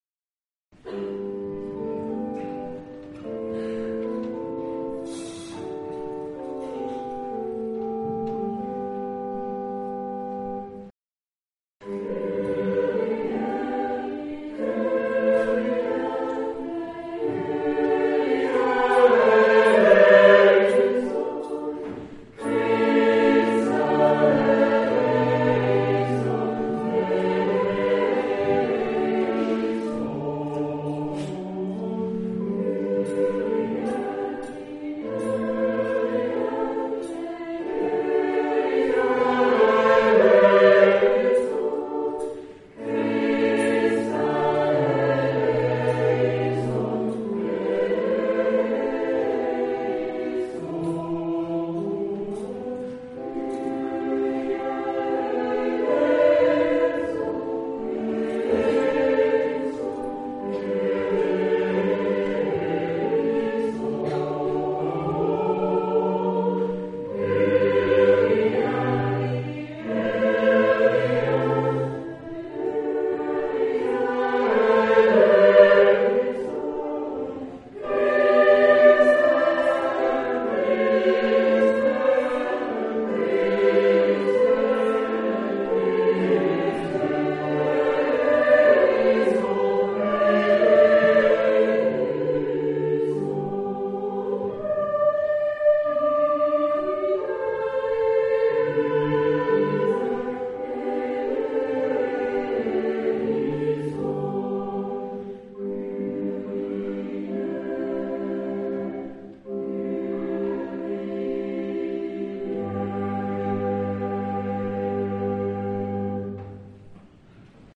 Hochamt - Ostersonntag 2009